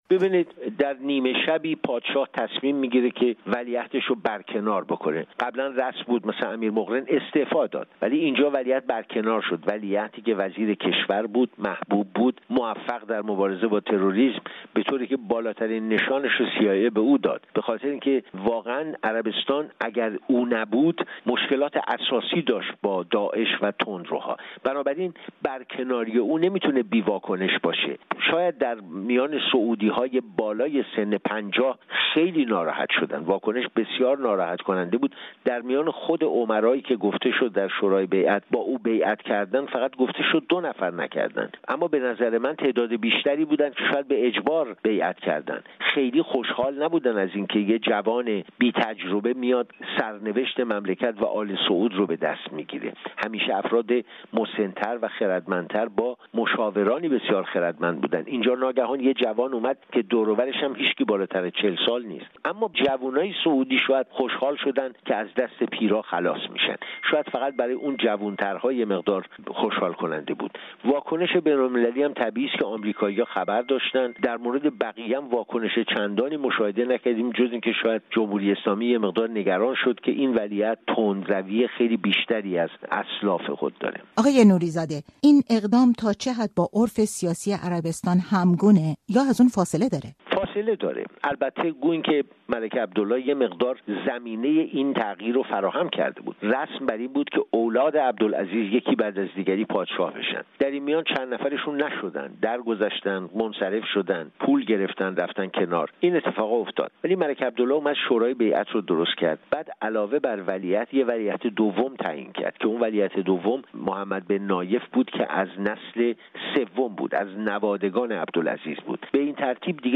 در گفت و گو با رادیو فردا از عزل محمد بن نائف و نصبِ محمد بن سلمان بجای او می گوید.